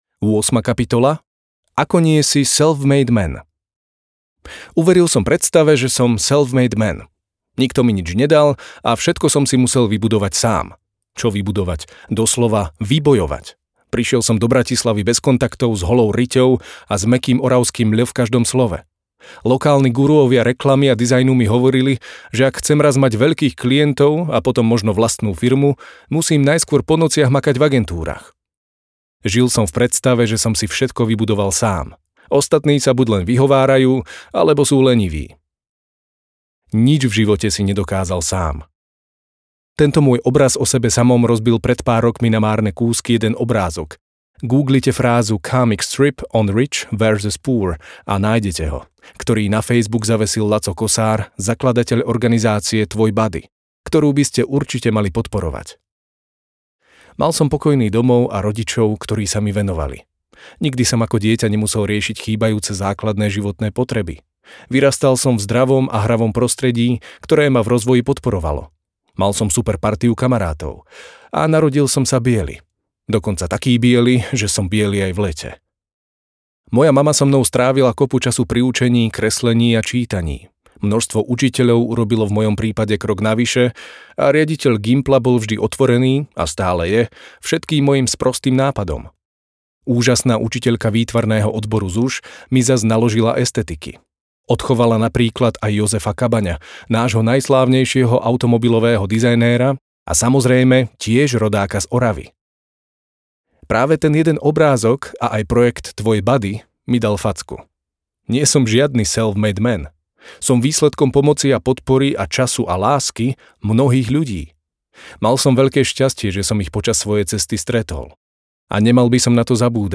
53 pokusov, ako byť lepší audiokniha
Ukázka z knihy